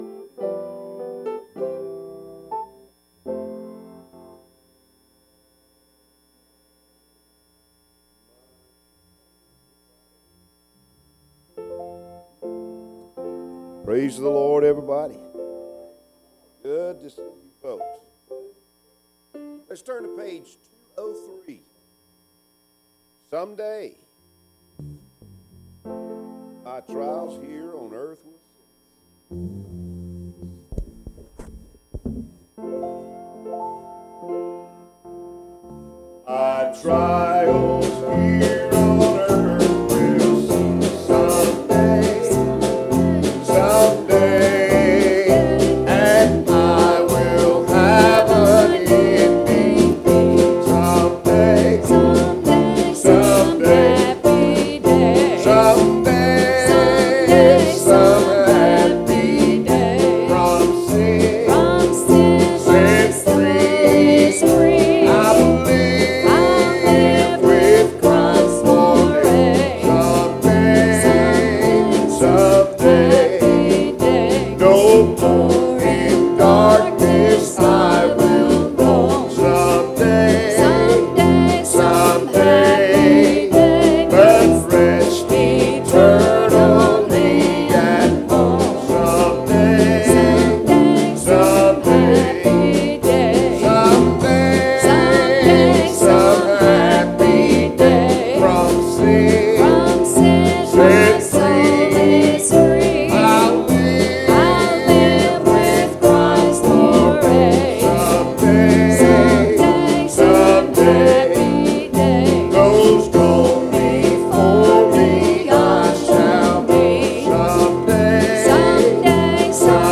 Service Type: Wednesday Evening Services